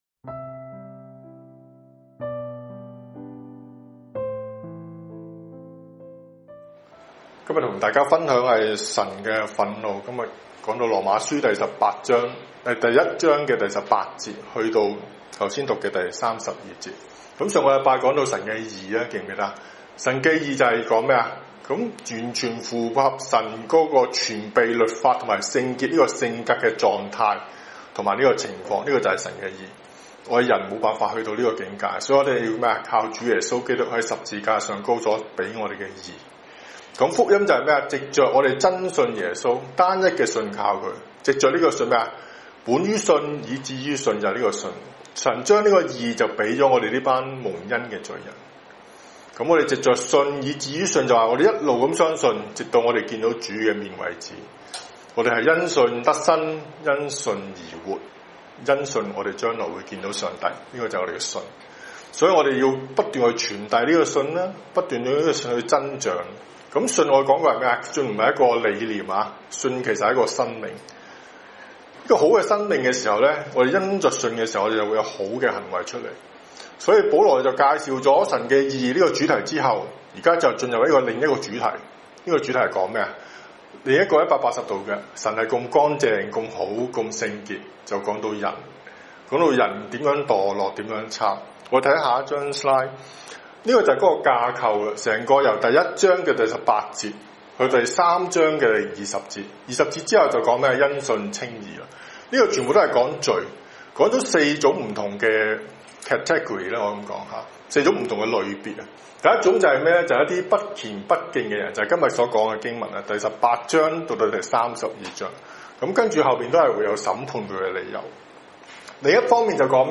神的忿怒[11月1日香港主日崇拜]
香港主日崇拜 ｜ 神的忿怒